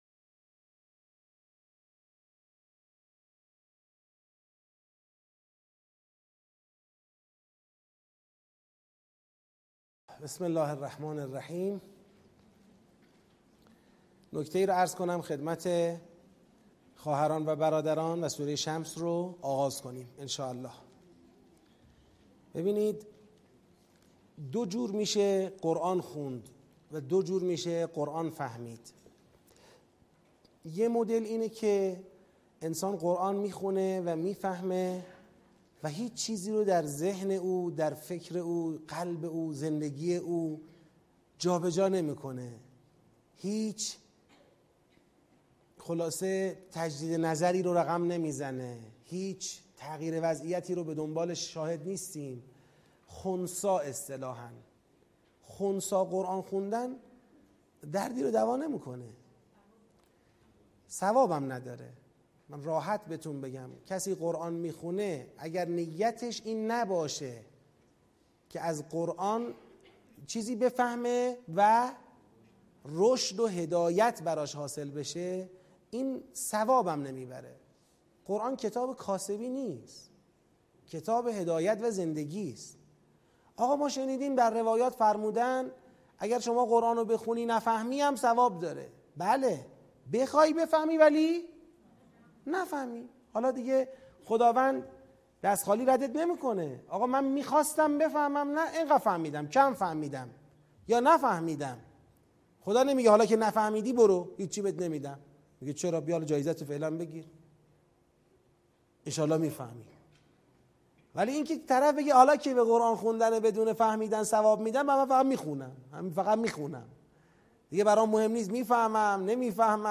با حضور ۲۵۰ نفر از قرآن آموزان در مسجد پیامبر اعظم (ص) شهرک شهید محلاتی تهران آغاز شد.